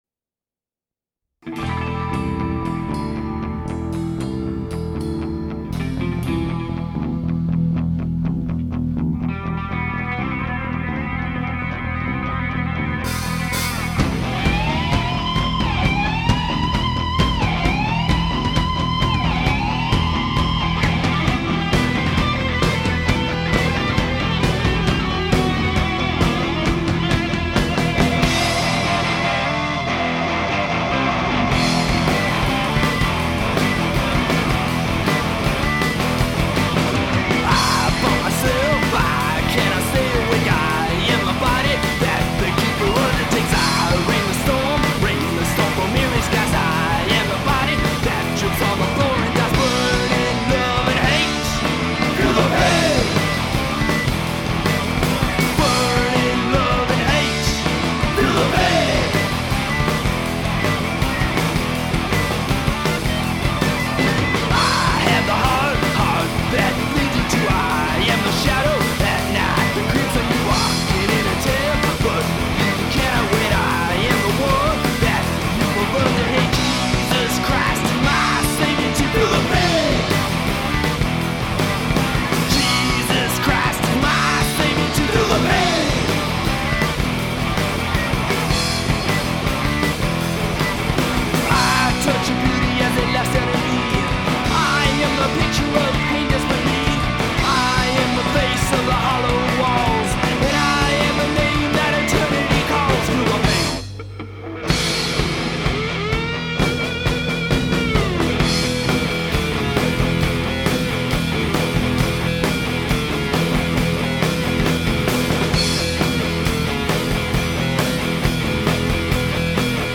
Recorded at Public Recordings, Brea CA